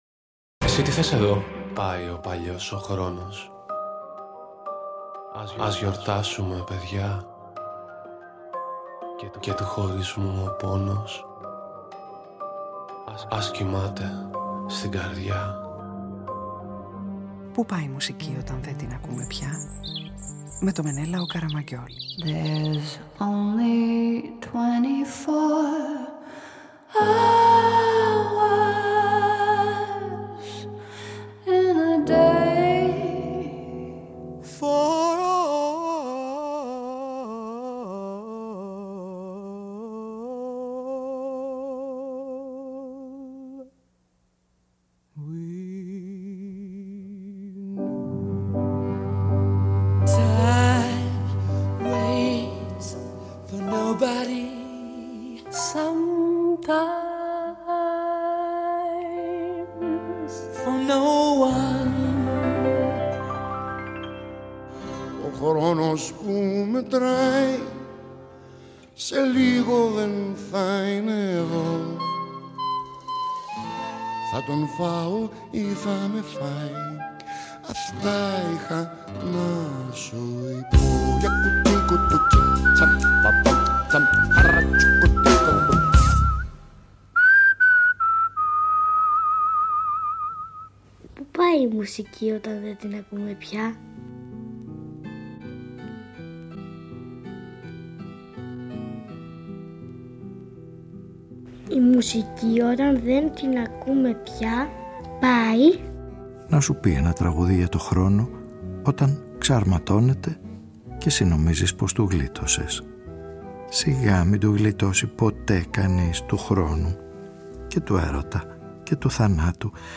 Οδηγίες χρήσης -για όσους αγχώνονται με το χρόνο και το πέρασμα του- γίνονται οι «οδηγοί» σε μια ραδιοφωνική ταινία που επαναπροσδιορίζει το χρόνο και τη μνήμη και γίνονται η «βάρκα» που χρειαζόμαστε όλοι καθημερινά.